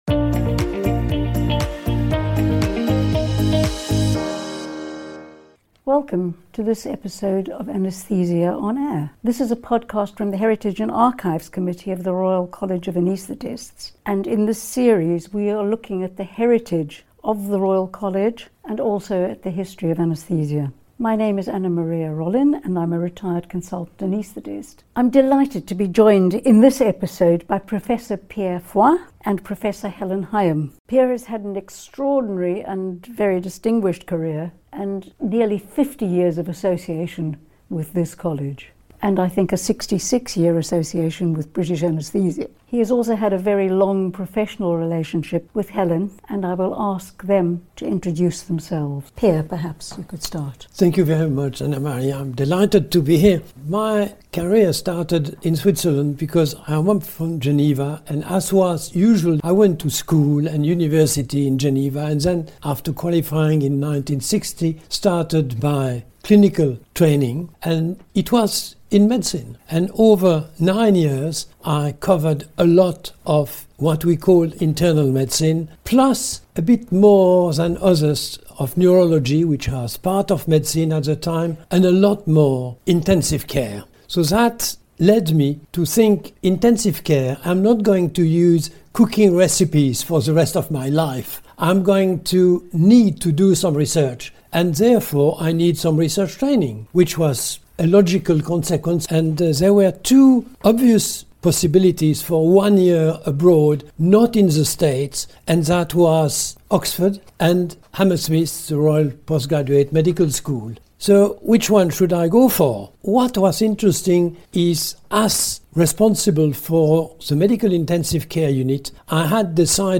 Anaesthesia on Air / A Conversation